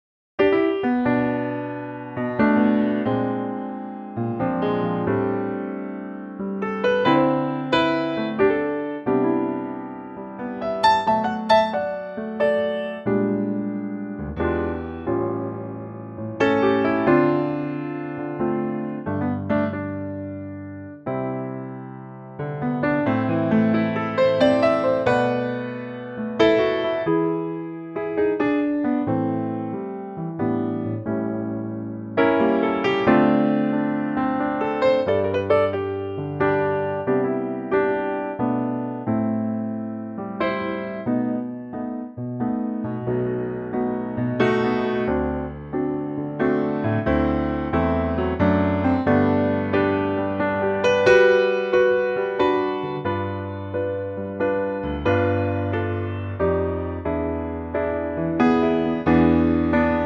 Unique Backing Tracks
key C (chorus is in G)
key - C - vocal range - B to E
Lovely piano only arrangement